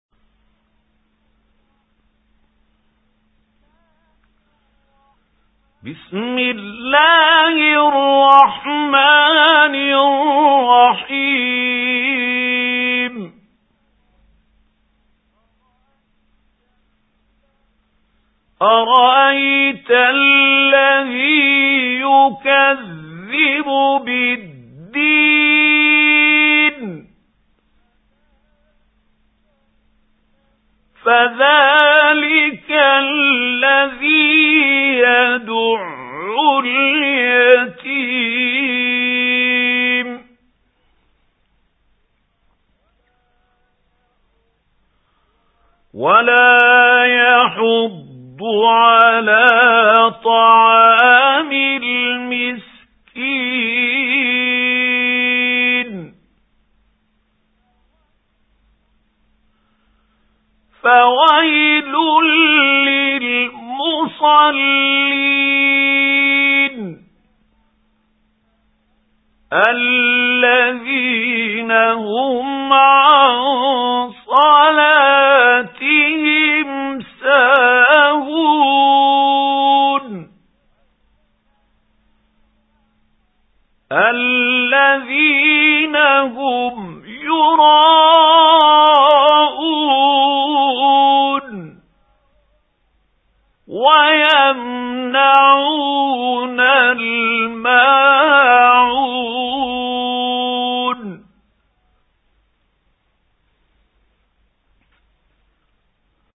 سُورَةُ المَاعُونِ بصوت الشيخ محمود خليل الحصري